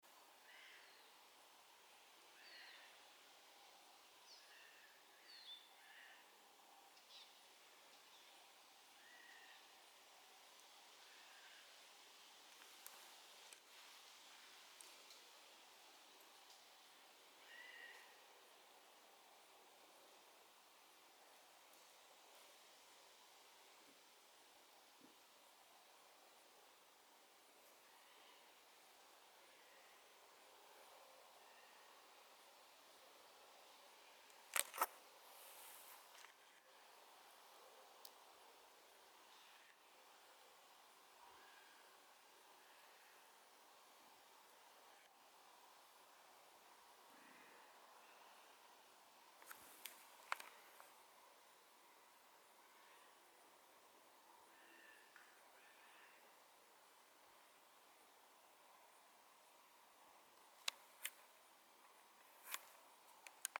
Sīlis, Garrulus glandarius
PiezīmesIztraucēju mežmalā kokā.
Ieraksts sanāca slikts, jo mēģināju pieiet tuvāk, kamēr bļaustījās.